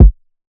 MURDA_KICK_WFH.wav